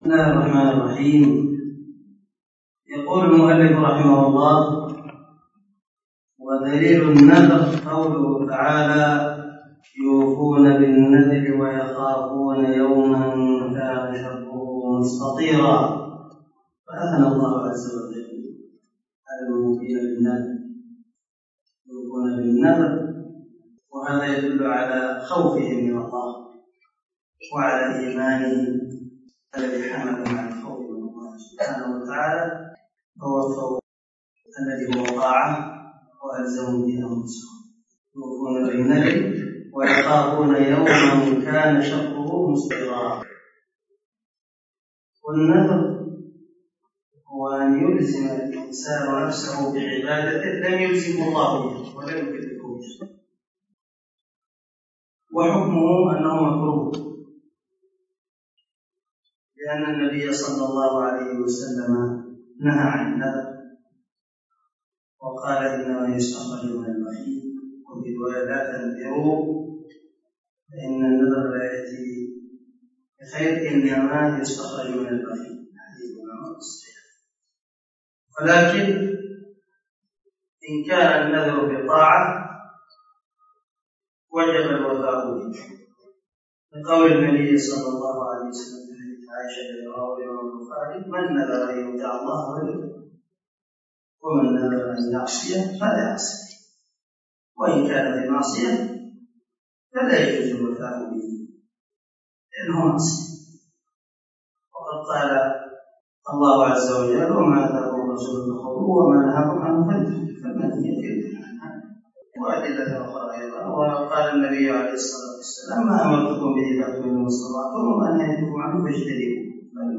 🔊 الدرس 21 من شرح الأصول الثلاثة
الدرس-21-ودليل-النذر.mp3